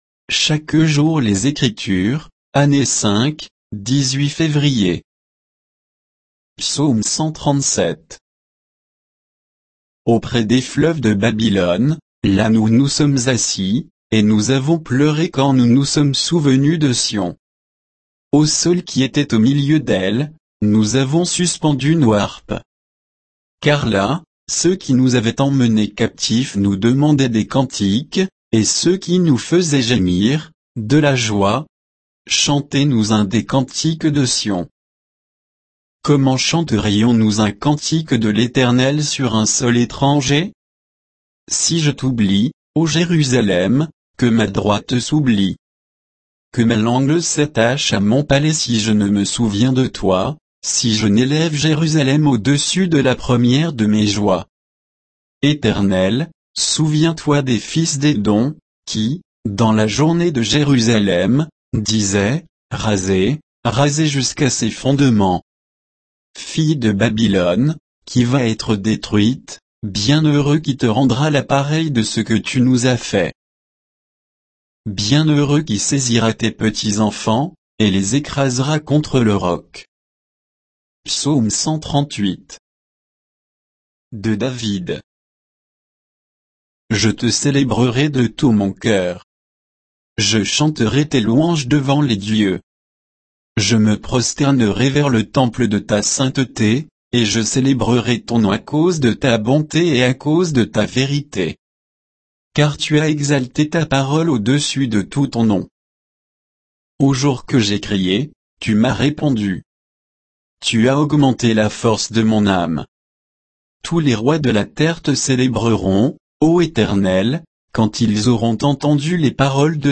Méditation quoditienne de Chaque jour les Écritures sur Psaumes 137 et 138